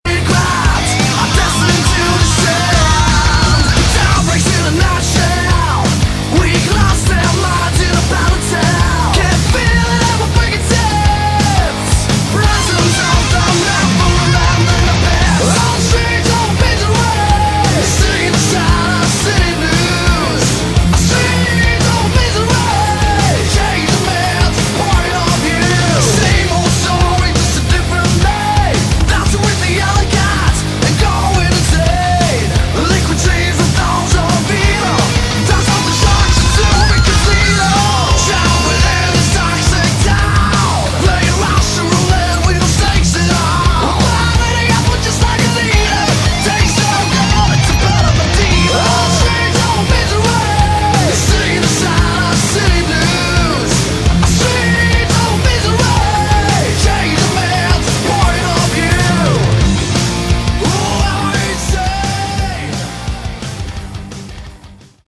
Category: Hard Rock
I like the more dangerous, youth gone wild sleazy edge.